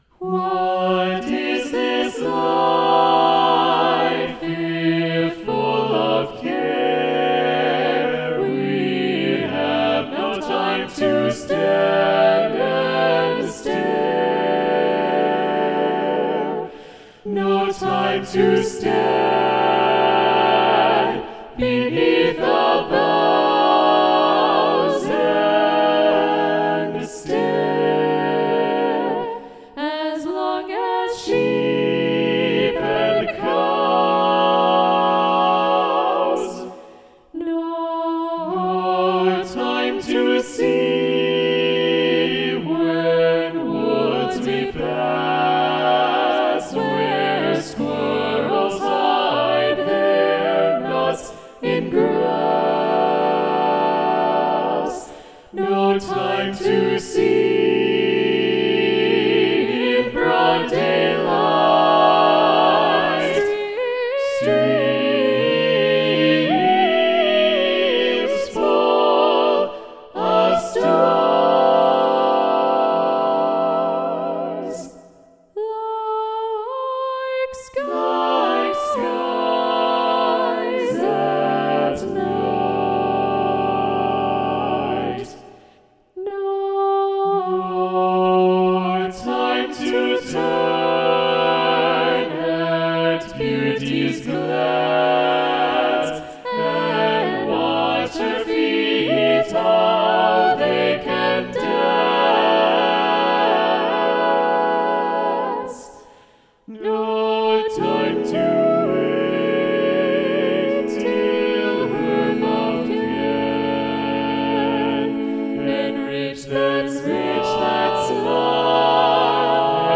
Voicing: SATB divisi
Instrumentation: a cappella